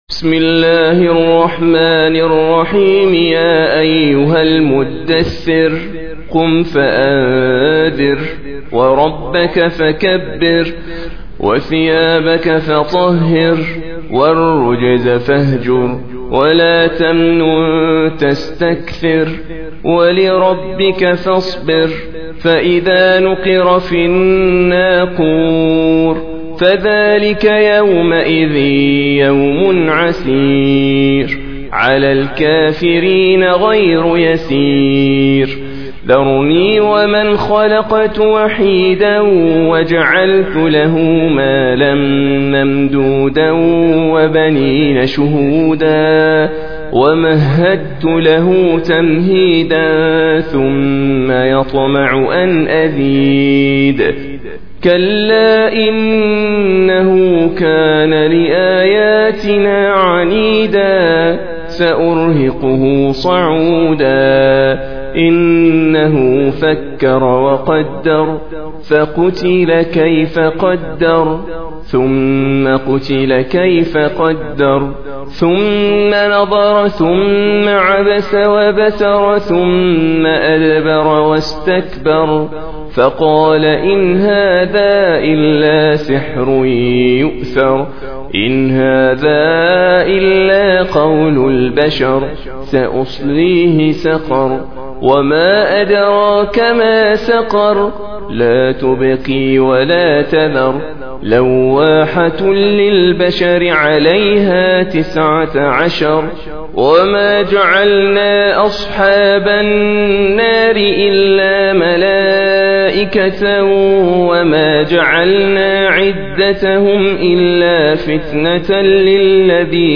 Surah Sequence تتابع السورة Download Surah حمّل السورة Reciting Murattalah Audio for 74. Surah Al-Muddaththir سورة المدّثر N.B *Surah Includes Al-Basmalah Reciters Sequents تتابع التلاوات Reciters Repeats تكرار التلاوات